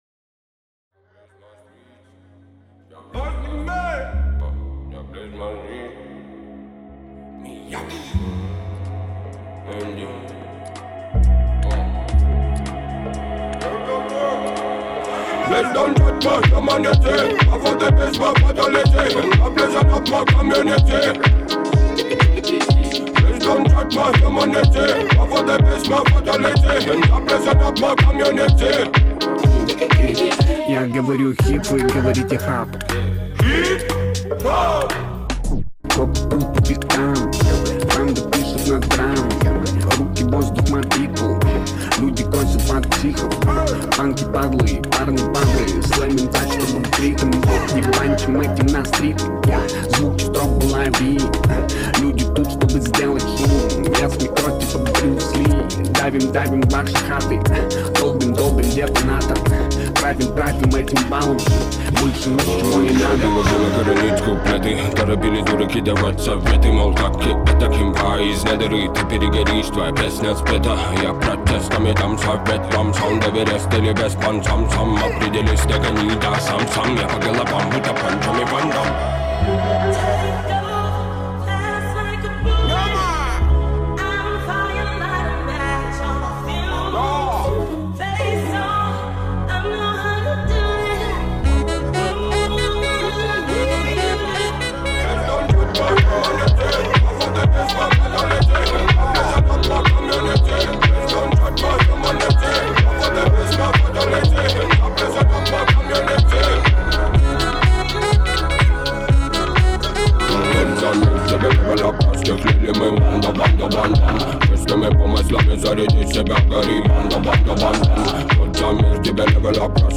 это яркая композиция в жанре хип-хоп и рэп